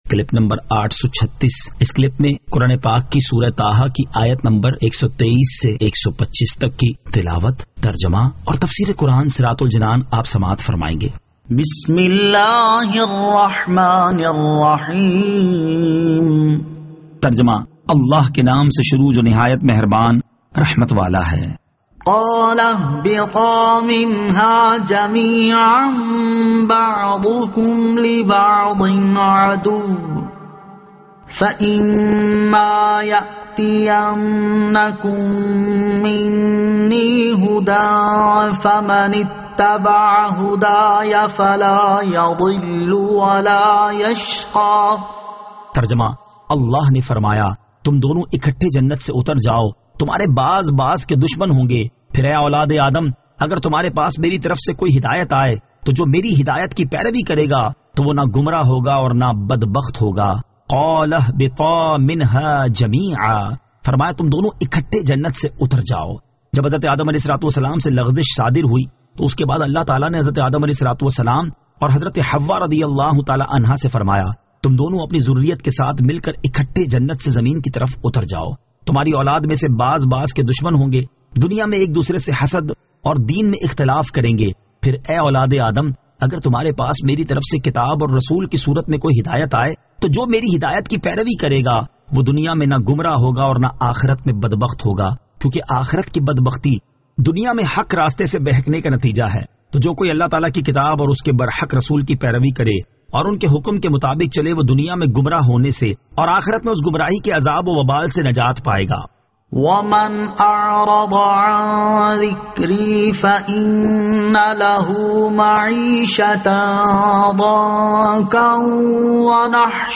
Surah Taha Ayat 123 To 125 Tilawat , Tarjama , Tafseer